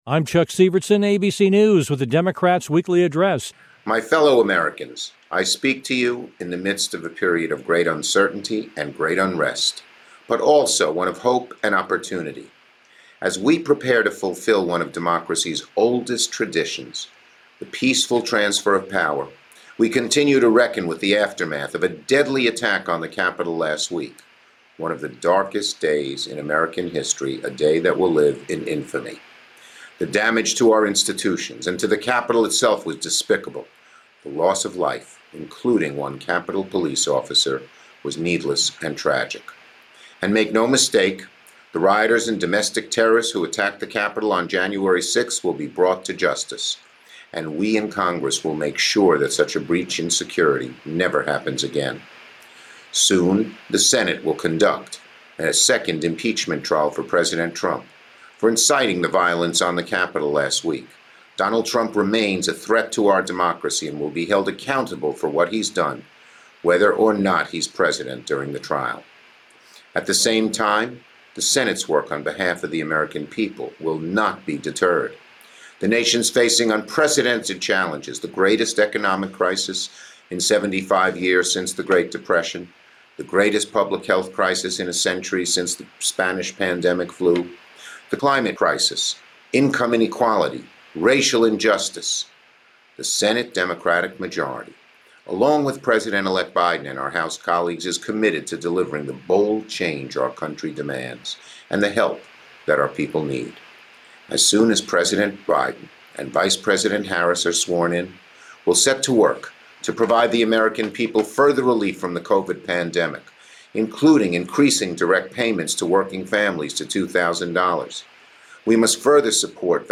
During the Democratic Weekly Address, incoming Senate Majority Leader Chuck Schumer (D-NY) stated that Senate Democrats will conduct a rigorous investigation of the events leading up to the Capitol riot.
Here are his words: